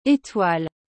• Som do “é” – Não é igual ao “é” do português. Pense no som do “ê” em “bebê”.
• Som do “oi” – Diferente do português, ele soa mais como “uá”.
• Ligação entre sílabas – O “l” final é sutil, quase sem som.
etoile.mp3